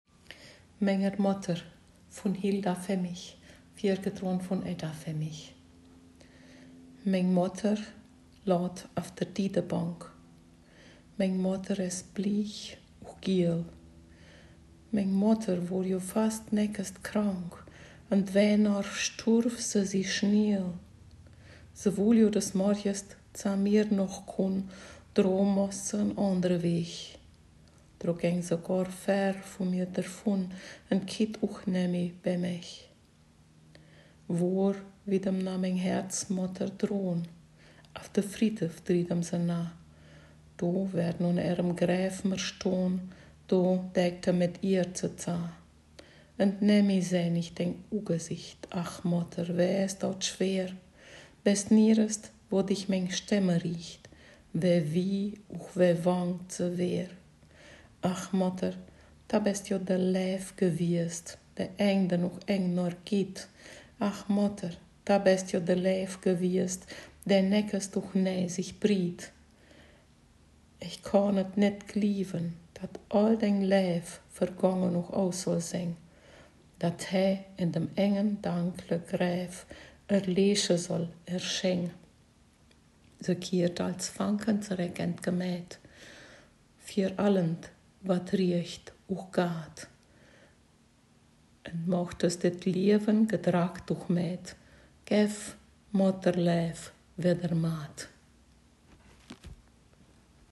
Ortsmundart: Hermannstadt